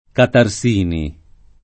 [ katar S& ni ]